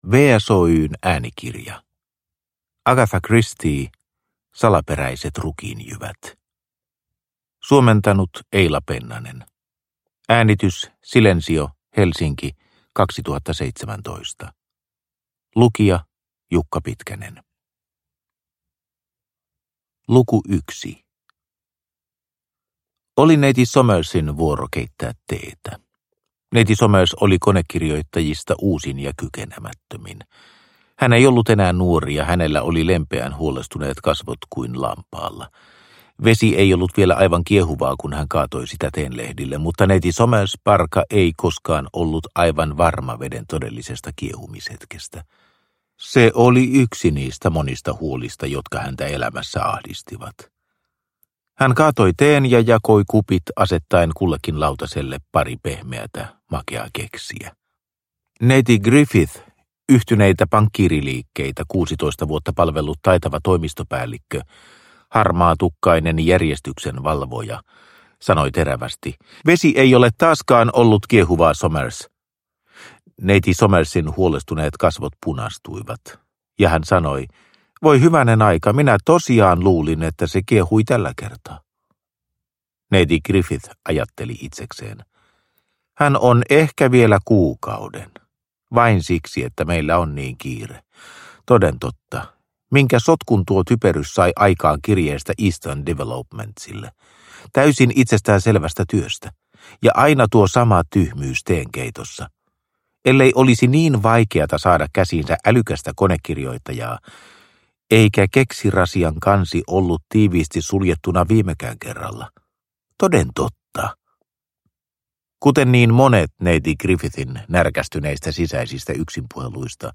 Salaperäiset rukiinjyvät – Ljudbok – Laddas ner